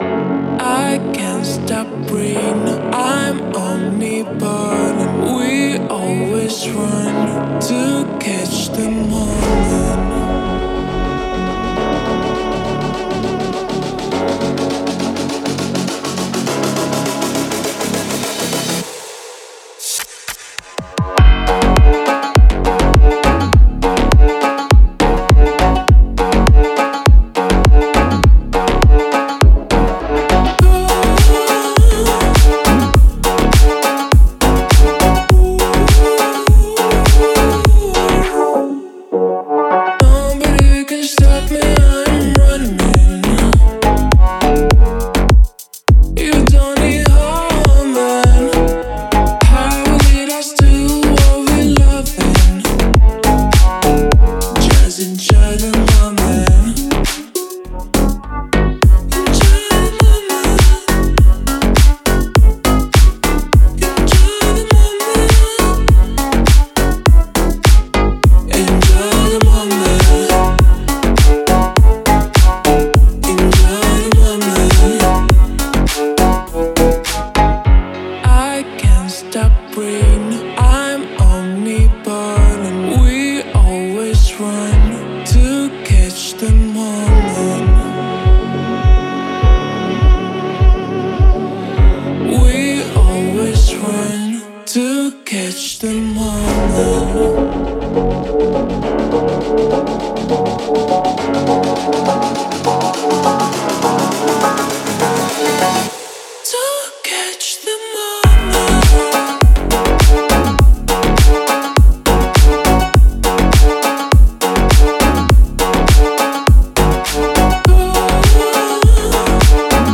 это трек в жанре инди-поп